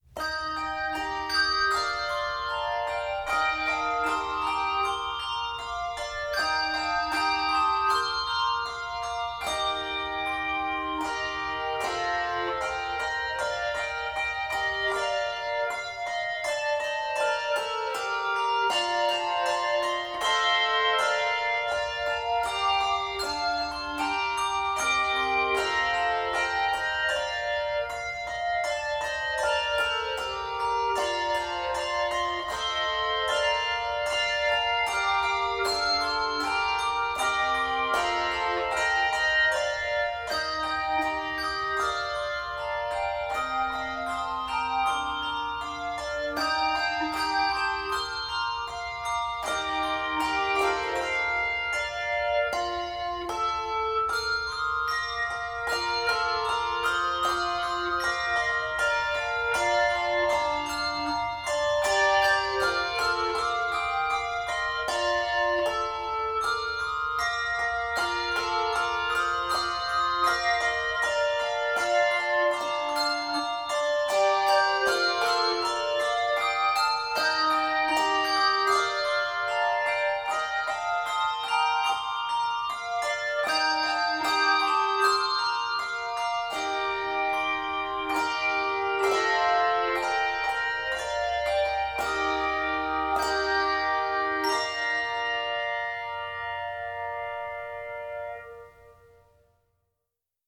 Key of Bb Major.